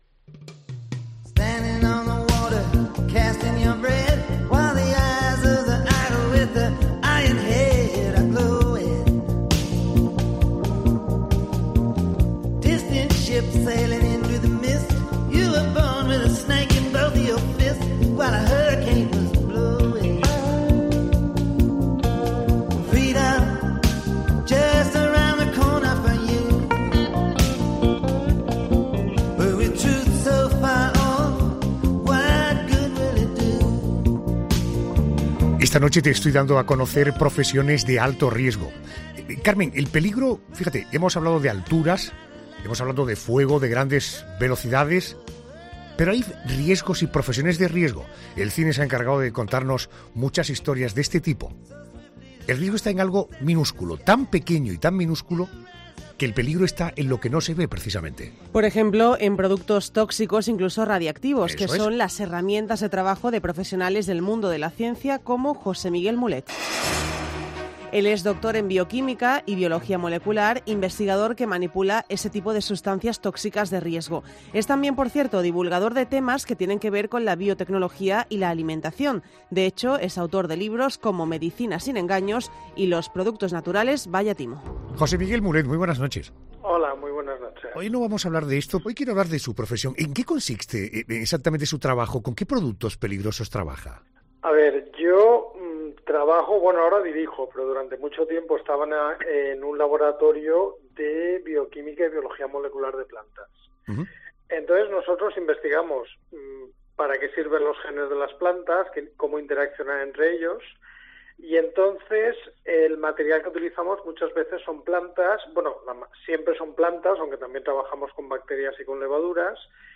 “Son semillas de una planta que no es endémica, entonces no podría hibridar con nadie y, lo más probable, es que se muriera”, asegura en una entrevista en 'La Noche de COPE'.